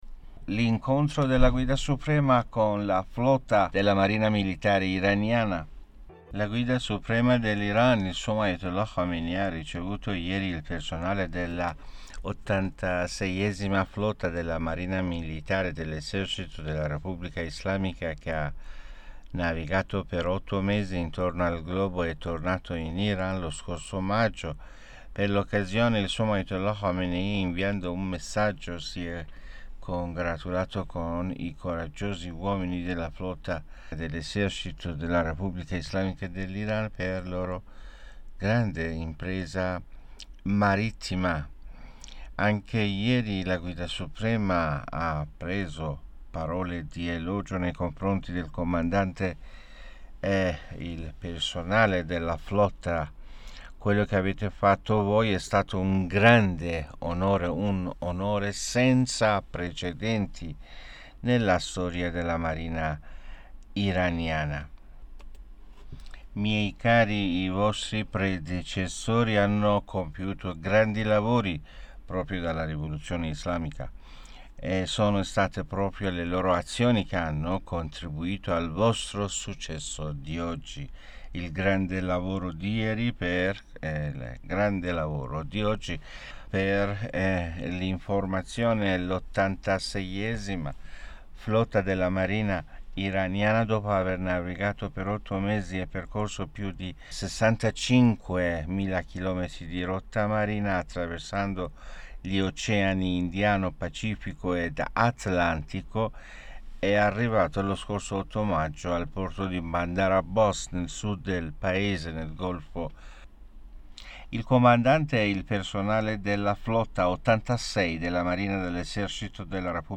(AUDIO) Discorso dell'Ayatollah Khamenei al personale della flotta 86